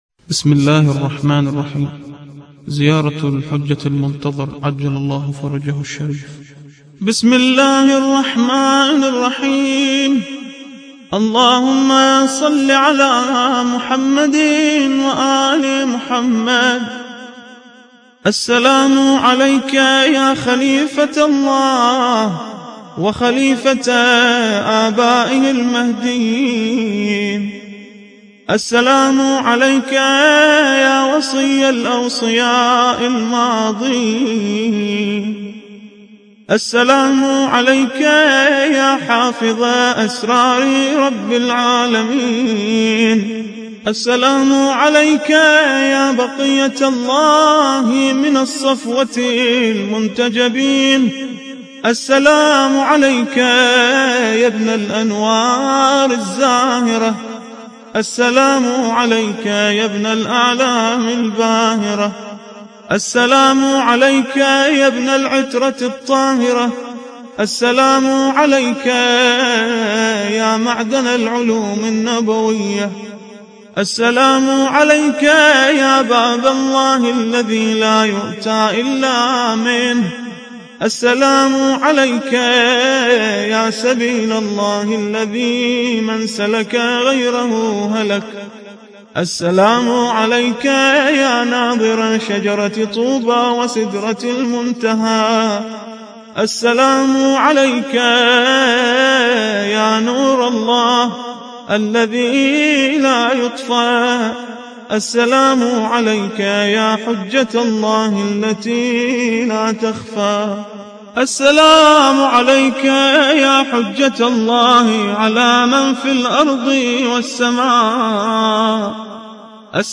زيارة الحجة المنتظر عجل الله فرجه الشريف – الرادود